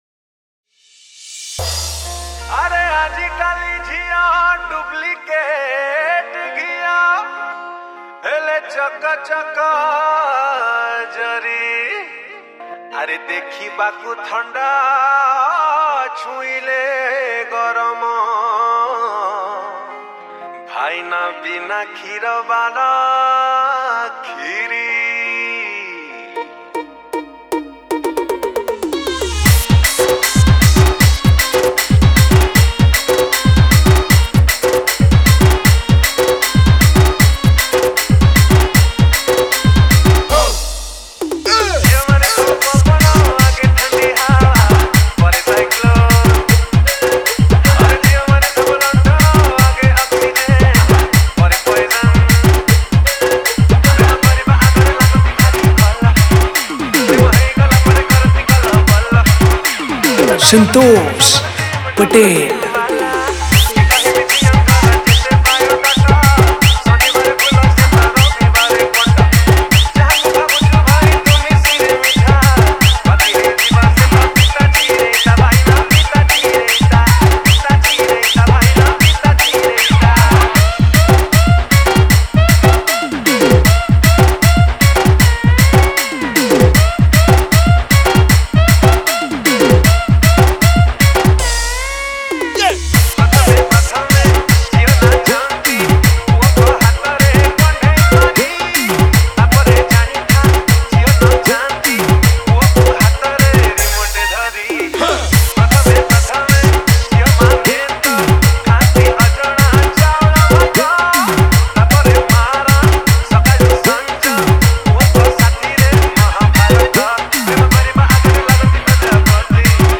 Category:  Odia New Dj Song 2019